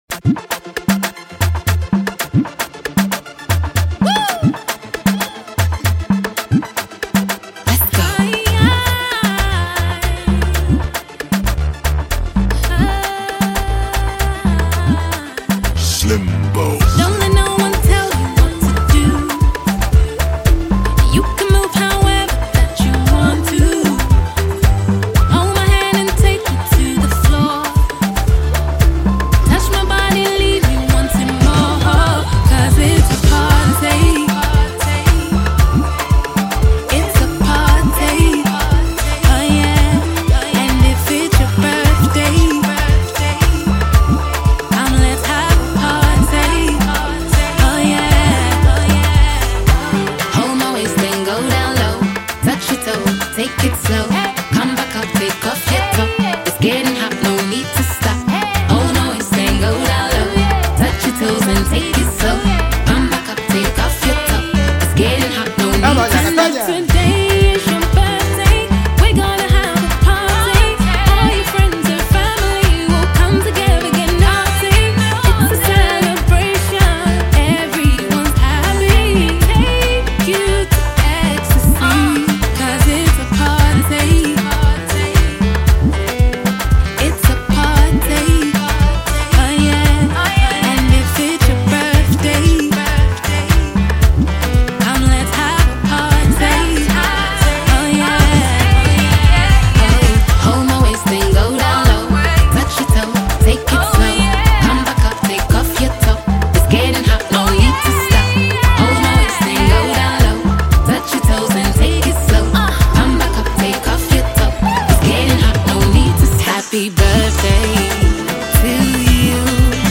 Ghana Music
songstress